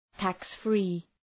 Προφορά
{,tæks’fri:}